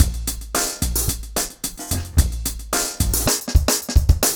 RemixedDrums_110BPM_09.wav